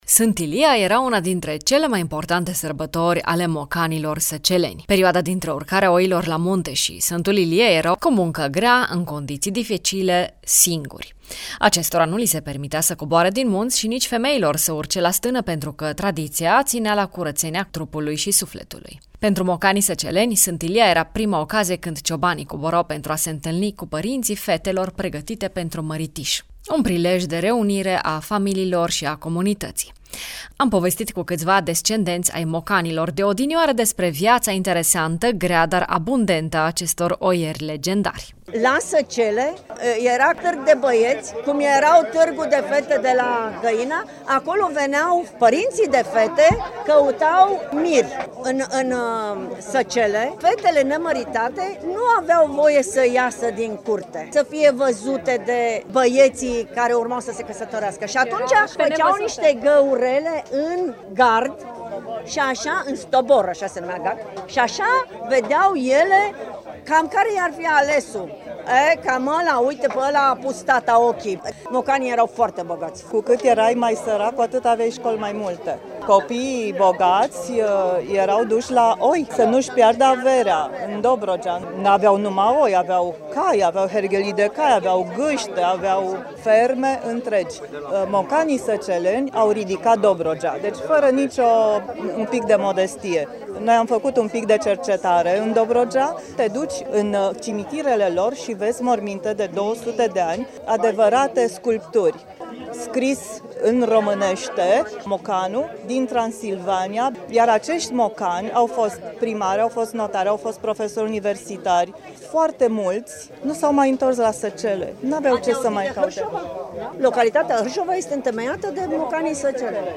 O sumedenie de recorduri în ediția din acest an a Festivalului Ceaunelor de la Borsec, față de anii anteriori, atât la numărul de echipe care au făcut demonstratii de gătit, cât și al celor participante la concursul bucătarilor, dar și la numărul de portii oferite: 3500!!
Mare succes a avut si balonul cu aer cald, o premieră în Borsec.